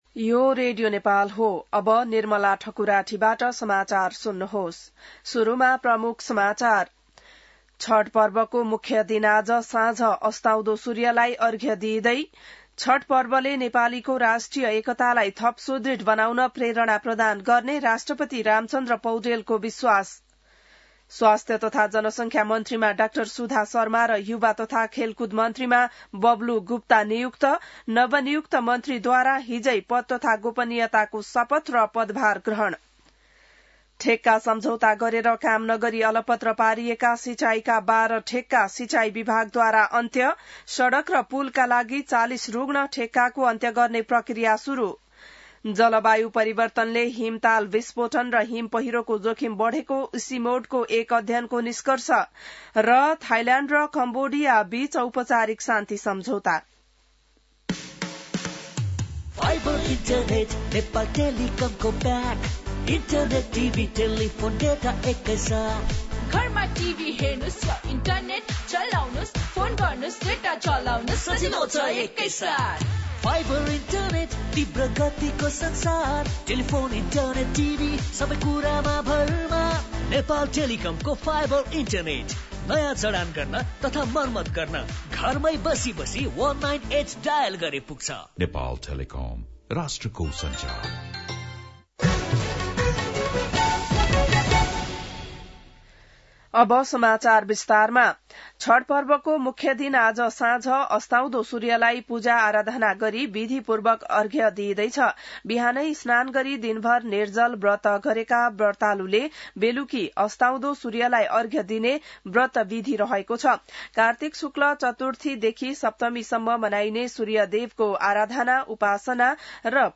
बिहान ७ बजेको नेपाली समाचार : १० कार्तिक , २०८२